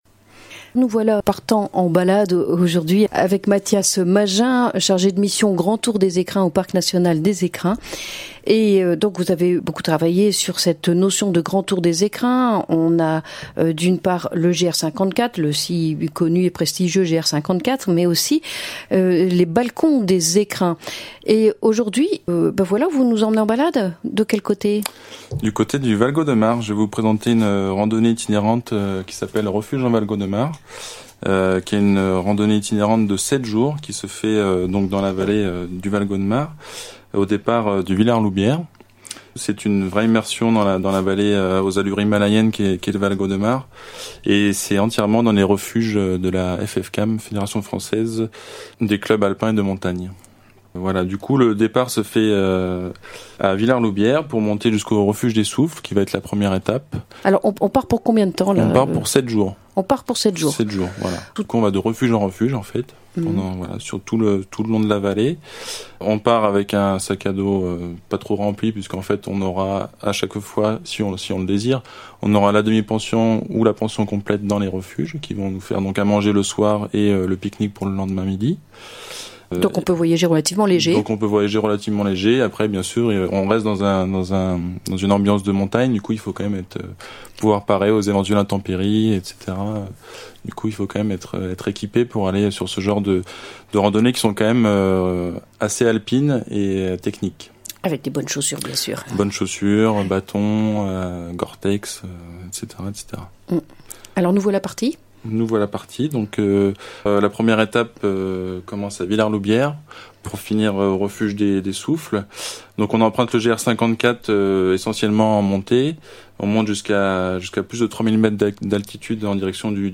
Les "chroniques nature" sont le fruit d'une émission hebdomadaire réalisée par la RAM, radio associative des Hautes-Alpes, avec l'intervention d'agents du Parc national des Écrins. Un dialogue autour de thématiques en connexion avec l'actualité des saisons ou sur des enjeux environnementaux pour le massif.